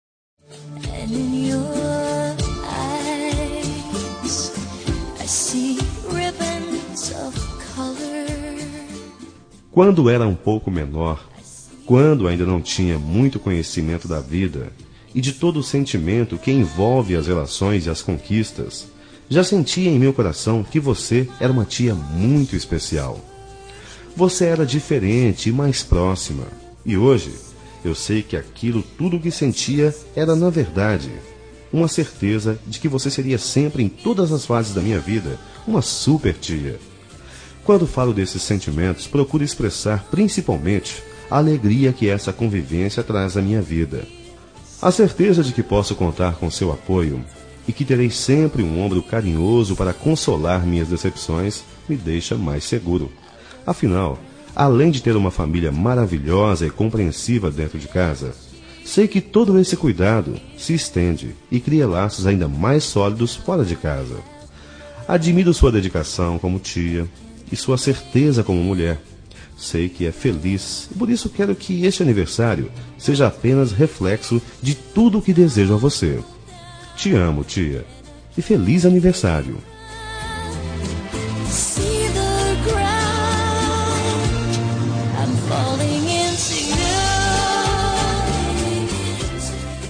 Telemensagem Aniversário de Tia – Voz Masculina – Cód: 2022